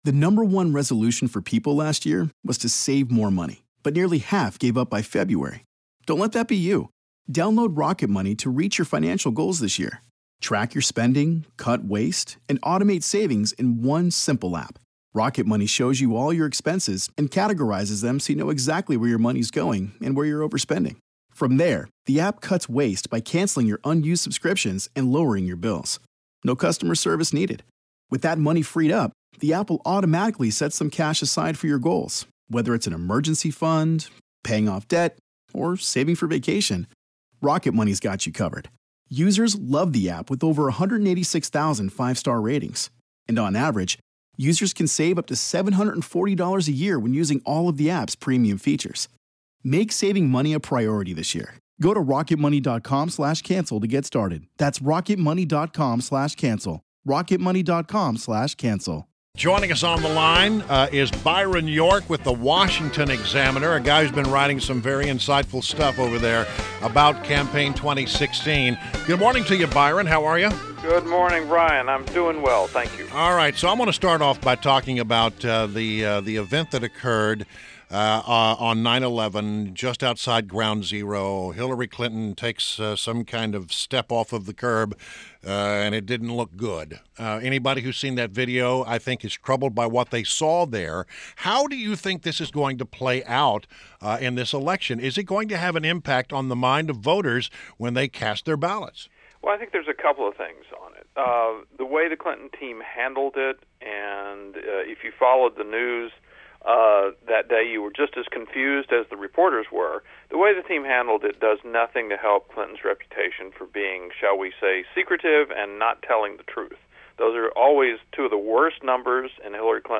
WMAL Interview - BYRON YORK - 09.14.16